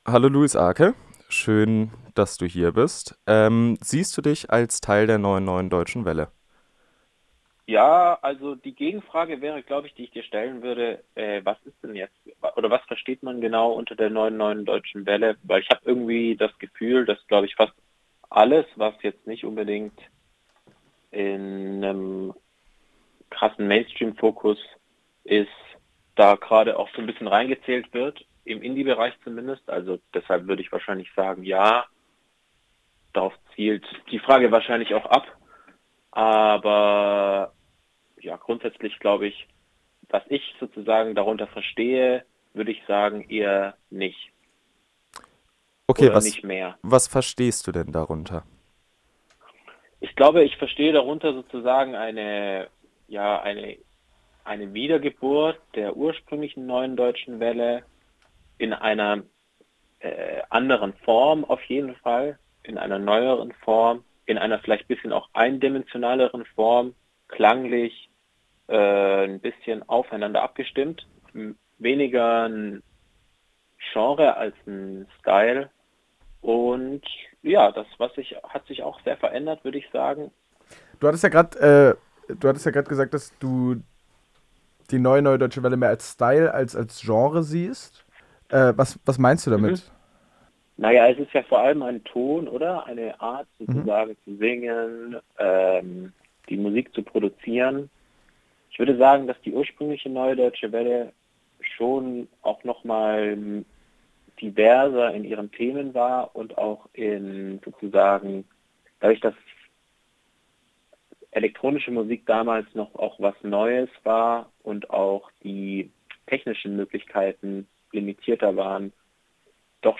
In insgesamt 5 Interviews sprechen wir mit Künstler*innen und einer Wissenschaftlerin über das Phänomen.